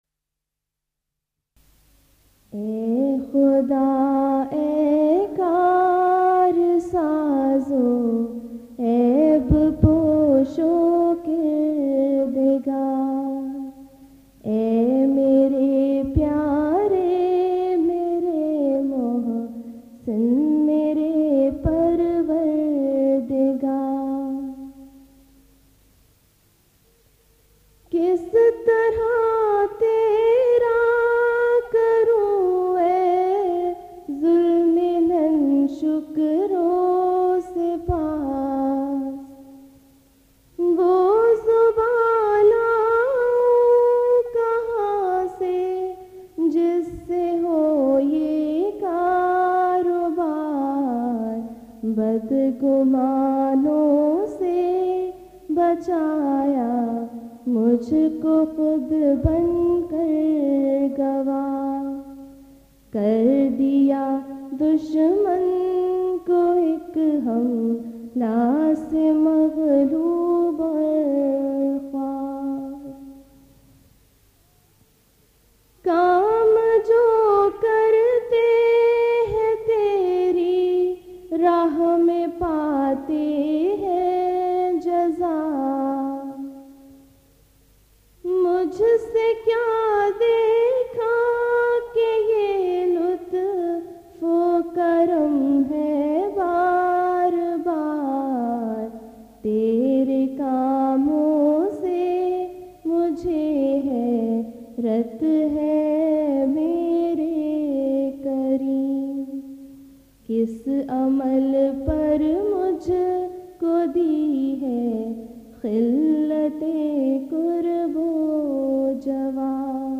Voice: Member Lajna Ima`illah